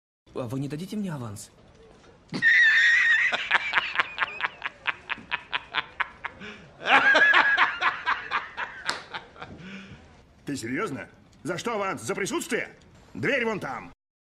Звуки Человека-Паука, паутины
Звук смеха Человека Паука из фильма